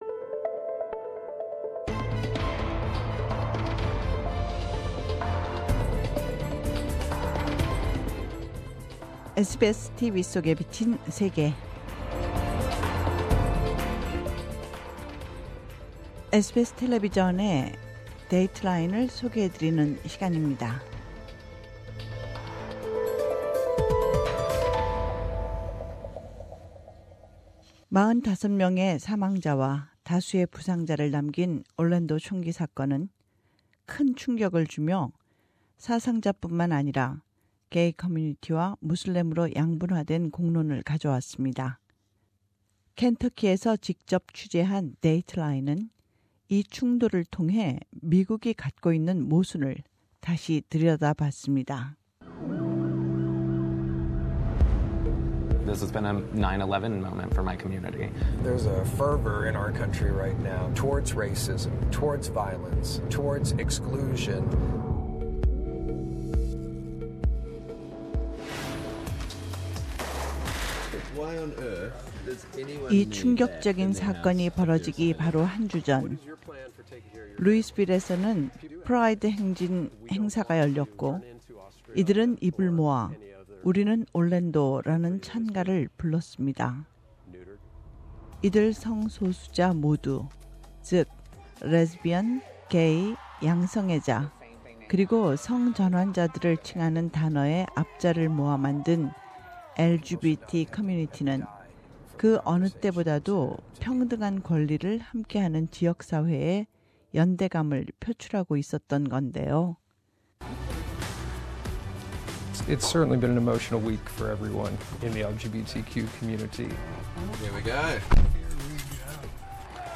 캔터키에서 직접 취재한 데이트라인은 이 충돌을 통해 미국이 갖고 있는 모순을 다시 들여다 봤습니다.